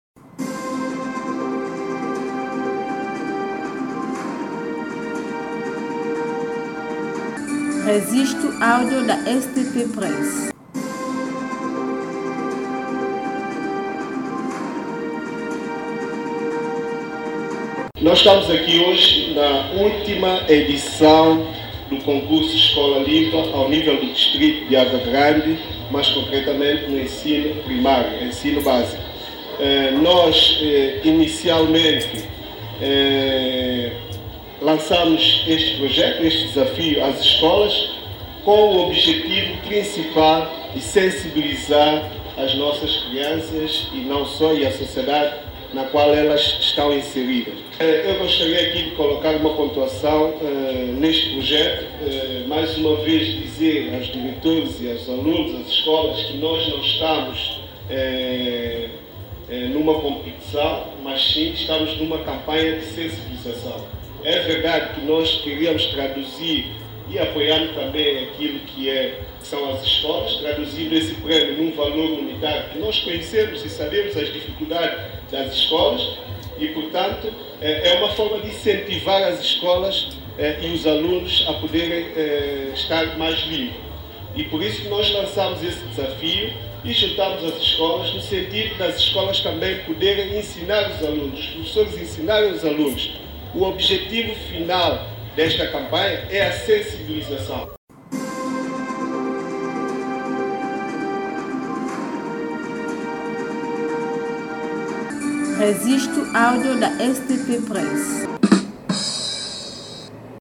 Declaração do Presidente do Água-Grande, Ekney Santos